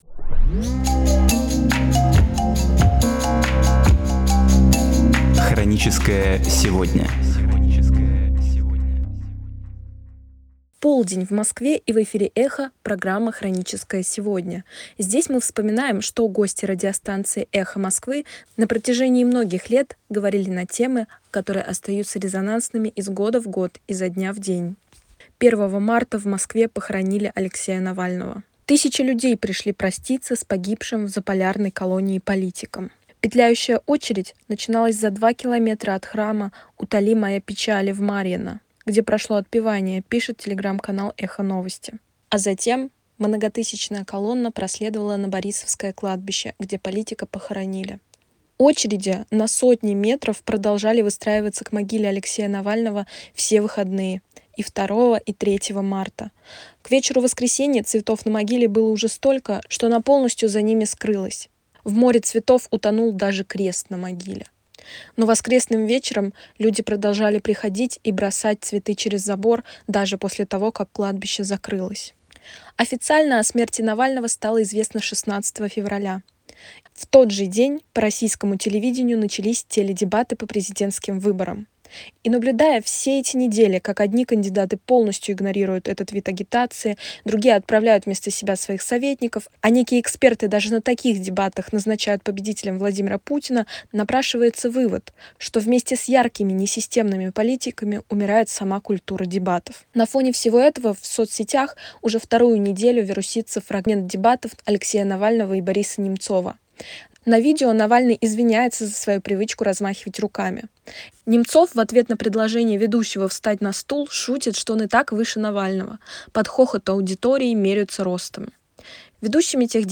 Архивные передачи «Эха Москвы» на самые важные темы дня сегодняшнего
Гости: Алексей Навальный, Борис Немцов, Гарри Каспаров, Олег Кашин